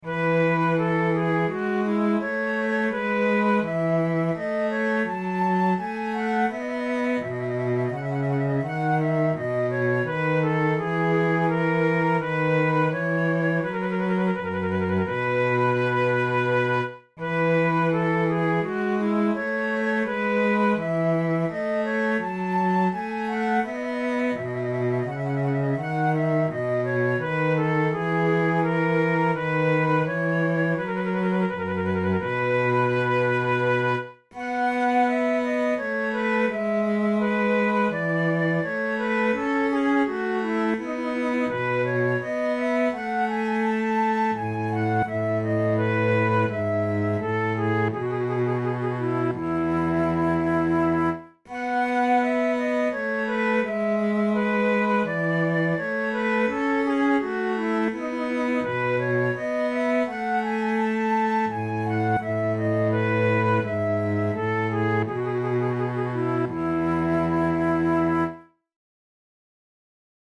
InstrumentationFlute and bass instrument
KeyE major
Time signature3/8
Tempo84 BPM
Baroque, Sonatas, Written for Flute